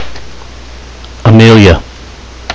Amelia wakewords from 8 speakers of varying ages, genders and accents.